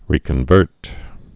(rēkən-vûrt)